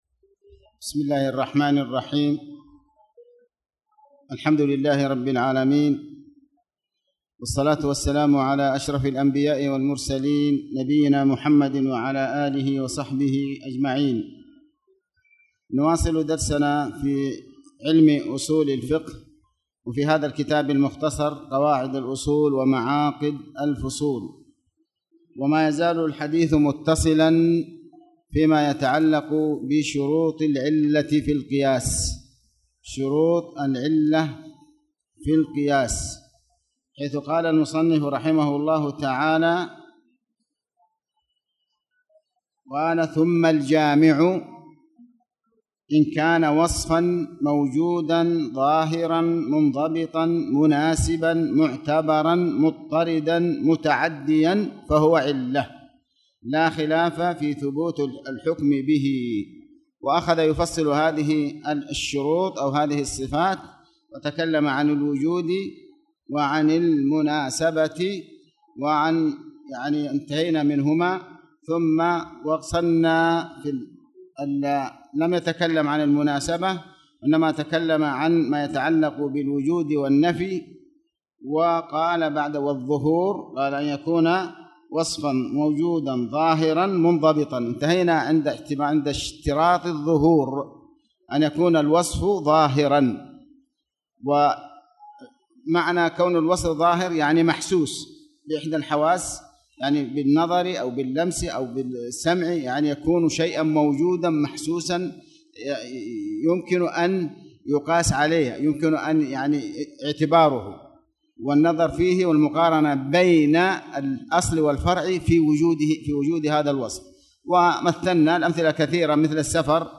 تاريخ النشر ٢٩ ربيع الأول ١٤٣٨ هـ المكان: المسجد الحرام الشيخ: علي بن عباس الحكمي علي بن عباس الحكمي شروط العلة في القياس